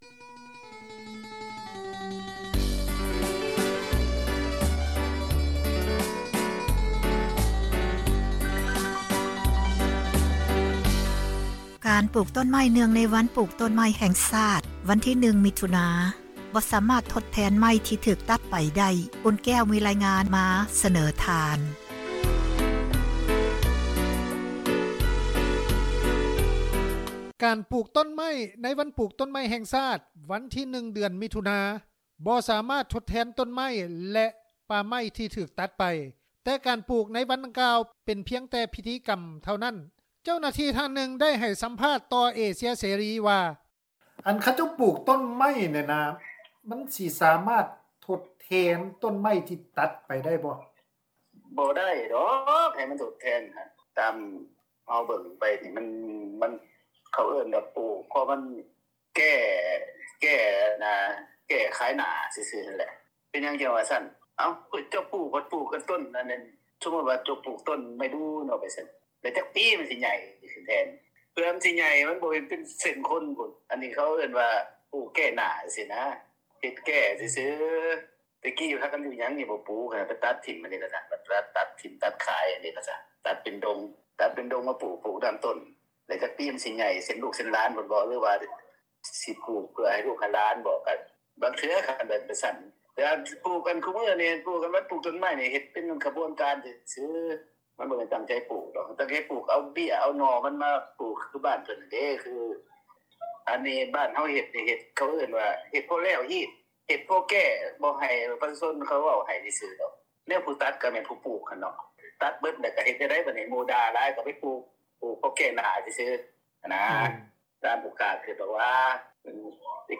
ເຈົ້າໜ້າທີ່ ທ່ານໜຶ່ງ ໄດ້ໃຫ້ສໍາພາດຕໍ່ເອເຊັຽເສຣີ ວ່າ:
ນອກຈາກນີ້, ປະຊາຊົນ ທ່ານໜຶ່ງ ໄດ້ໃຫ້ສໍາພາດຕໍ່ເອເຊັຽເສຣີ ວ່າ: